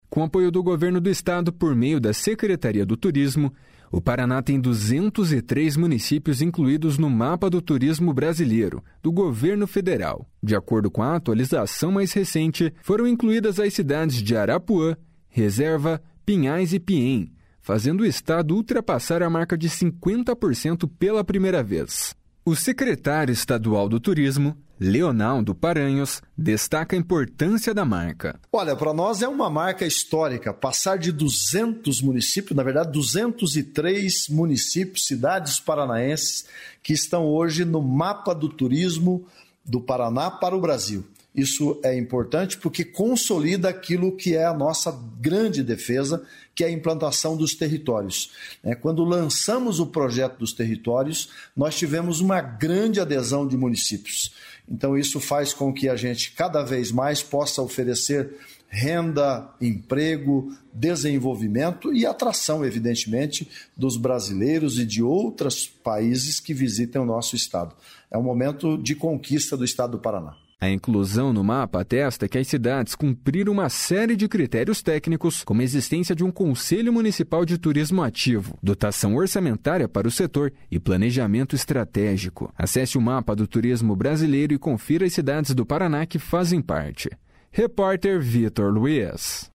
// SONORA LEONALDO PARANHOS //